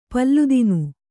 ♪ palludinu